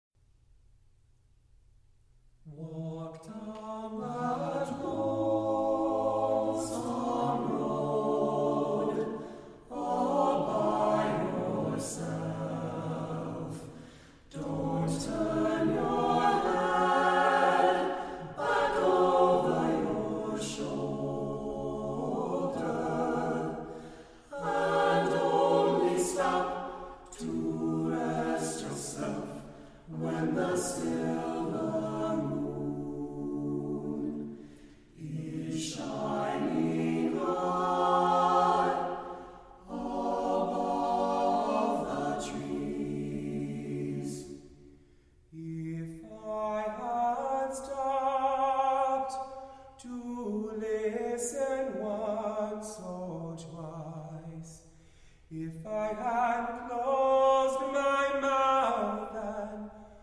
Piano
Vocal Ensemble